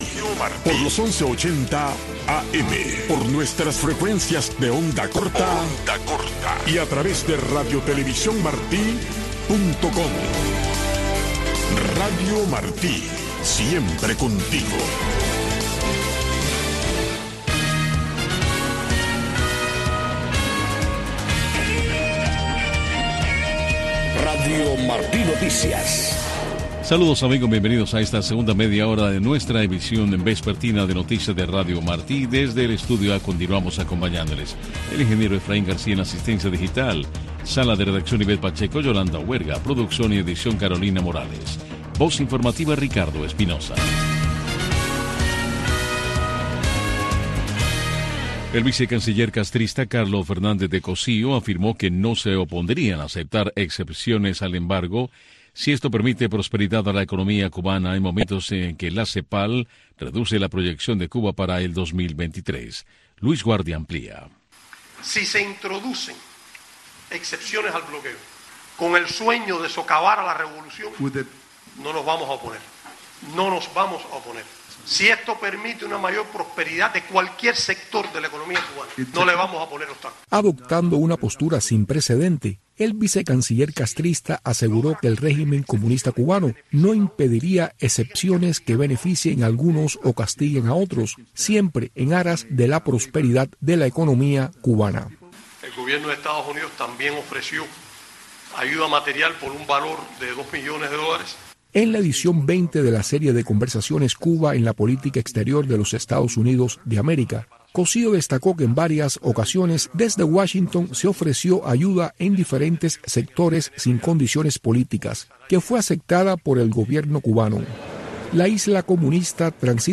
Noticiero de Radio Martí 5:00 PM | Segunda media hora